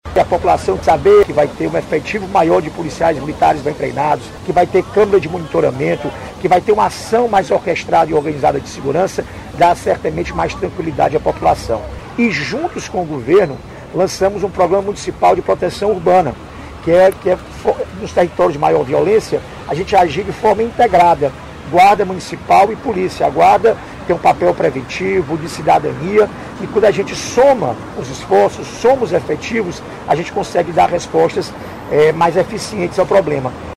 Roberto Cláudio afirmou que a qualidade de vida dos moradores de uma cidade depende de investimentos na Segurança Pública.